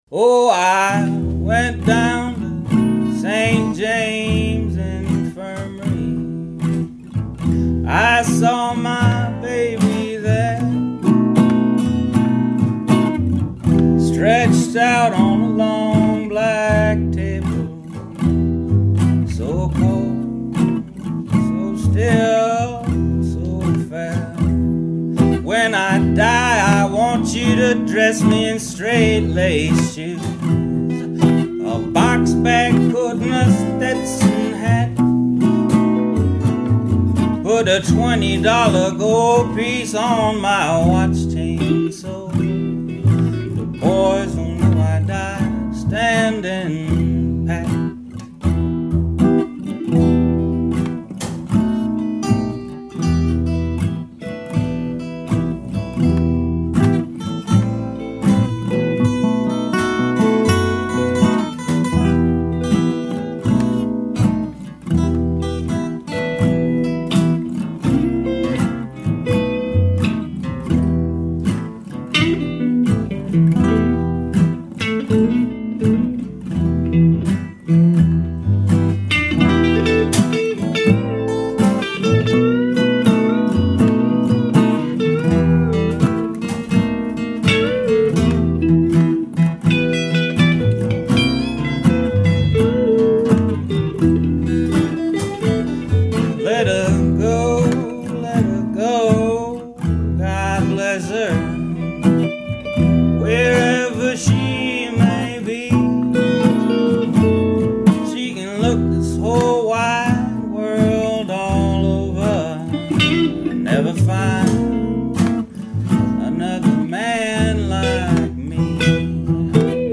vocal and rhythm guitar
acoustic guitar
electric guitar
bass.